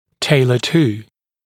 [‘teɪlə tuː][‘тэйлэ ту:]приспосабливать к, индивидуально подгонять к